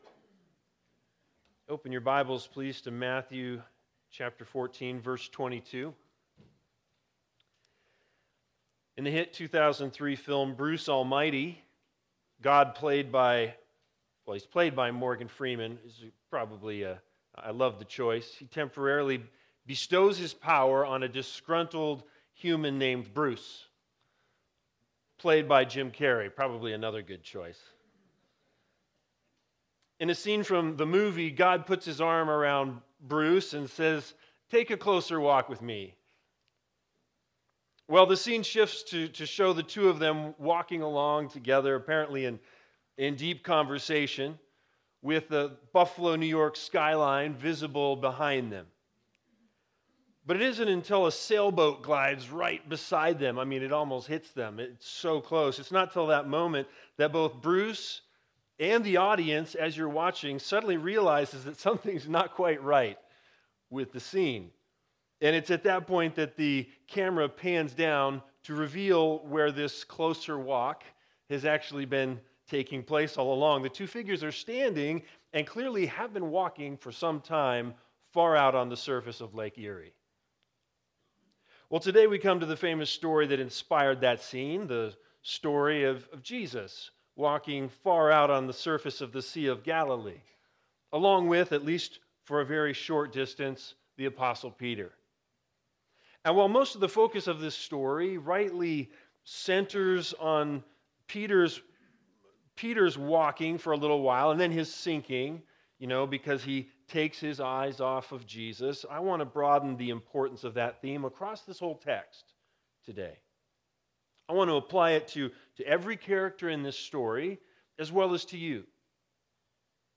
Matthew 14:22-36 Service Type: Sunday Sermons The Big Idea